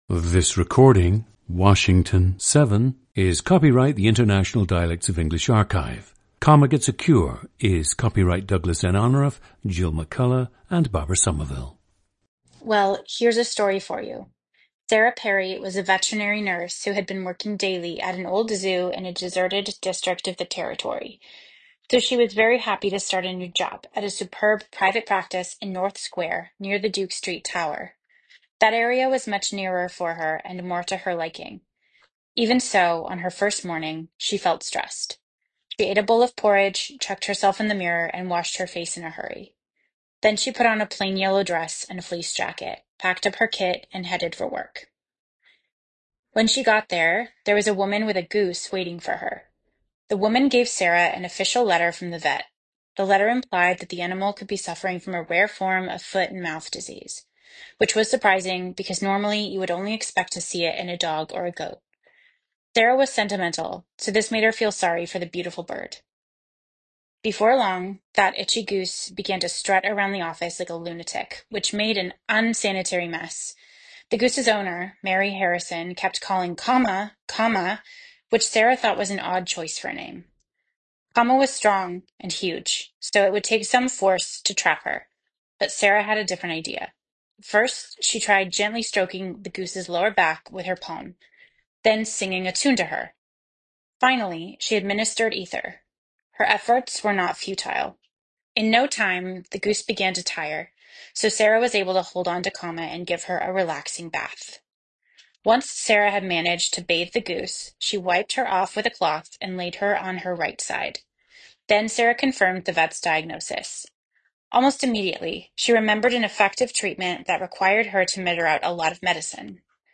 PLACE OF BIRTH: Olympia, Washington
GENDER: female
Depending on the environment (classroom, stage), her vowels may change.
• Recordings of accent/dialect speakers from the region you select.
The recordings average four minutes in length and feature both the reading of one of two standard passages, and some unscripted speech.